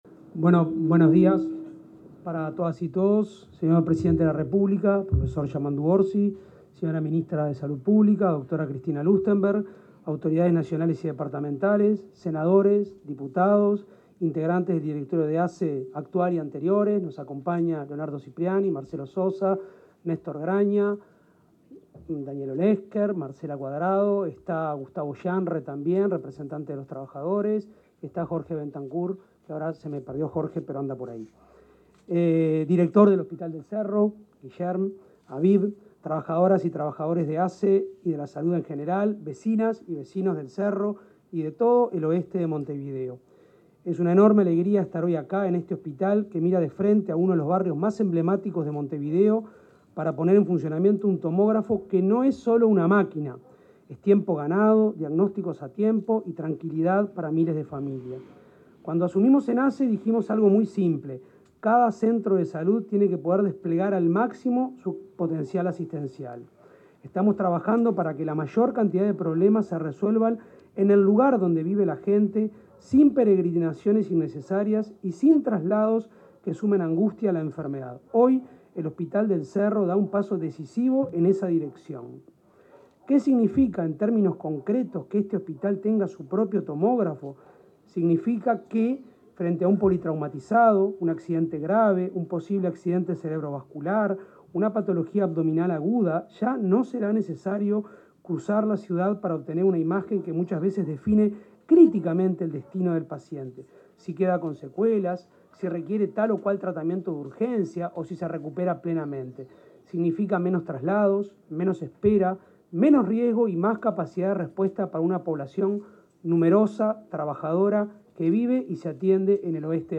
Palabras de la ministra de Salud Pública, Cristina Lustemberg, y el presidente de ASSE, Álvaro Danza
Palabras de la ministra de Salud Pública, Cristina Lustemberg, y el presidente de ASSE, Álvaro Danza 01/12/2025 Compartir Facebook X Copiar enlace WhatsApp LinkedIn El presidente de la República, Yamandú Orsi; la ministra de Salud Pública, Cristina Lustemberg, y el presidente de la Administración de los Servicios de Salud del Estado (ASSE), Álvaro Danza, inauguraron un tomógrafo en el Hospital del Cerro. Durante la ceremonia, Lustemberg y Danza disertaron acerca de la importancia de este nuevo equipamiento.